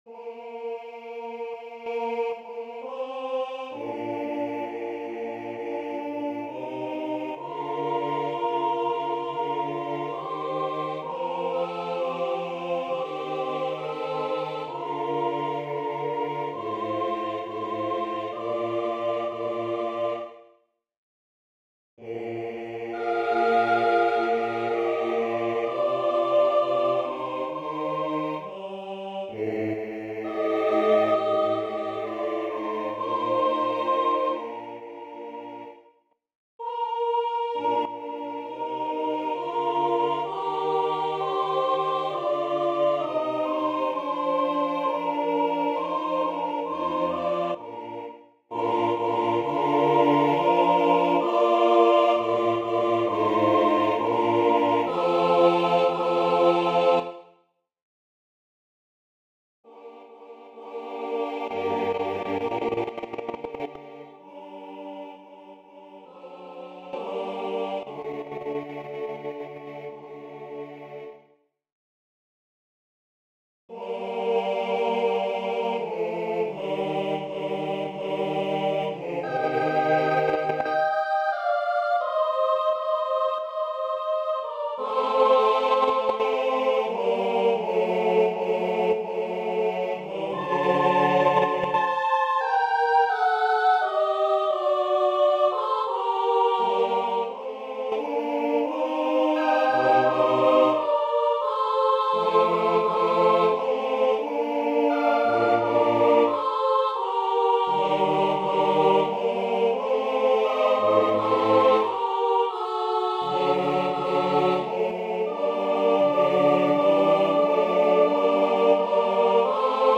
Концерт для смешанного хора и солистов, a cappella